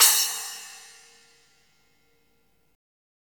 Index of /90_sSampleCDs/Roland L-CD701/CYM_FX Cymbals 1/CYM_Splash menu
CYM SPLAS04L.wav